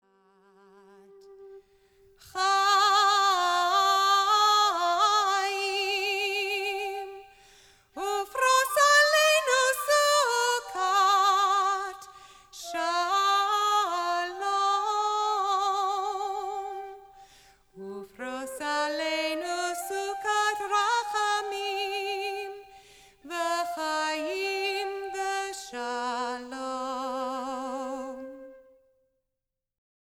Meditation
New Age